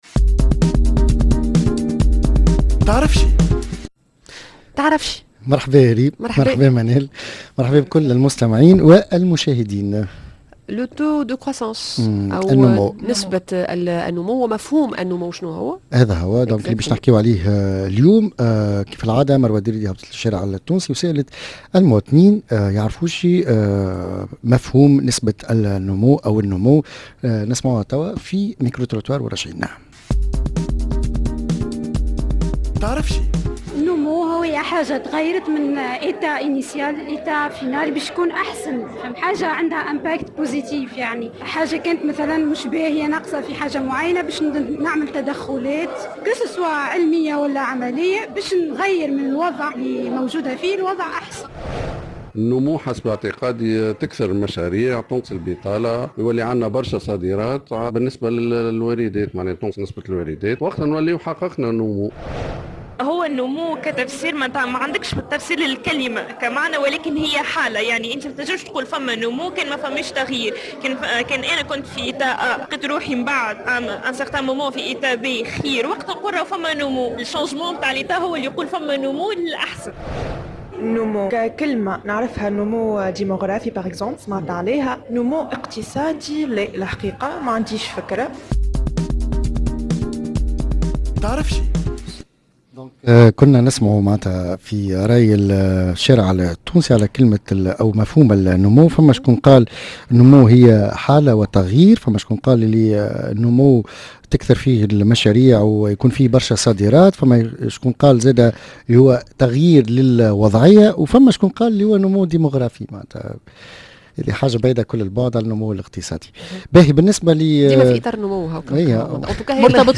(Micro trottoir)